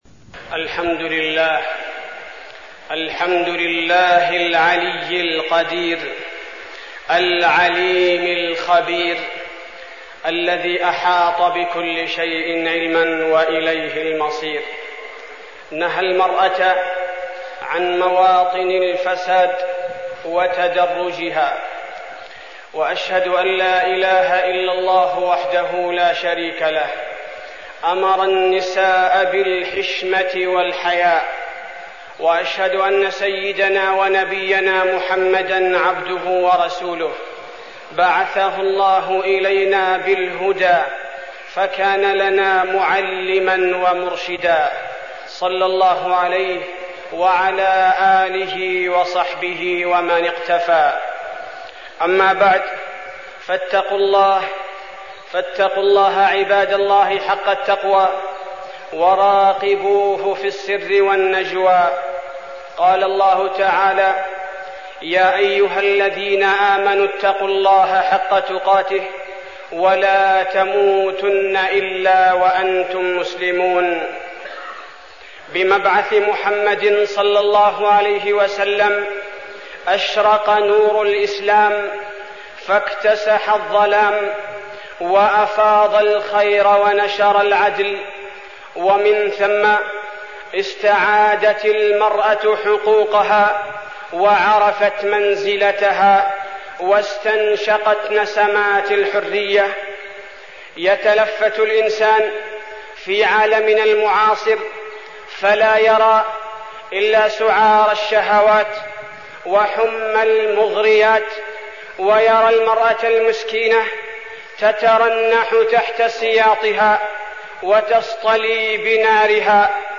تاريخ النشر ٢٩ صفر ١٤١٨ هـ المكان: المسجد النبوي الشيخ: فضيلة الشيخ عبدالباري الثبيتي فضيلة الشيخ عبدالباري الثبيتي حقوق المرأة المسلمة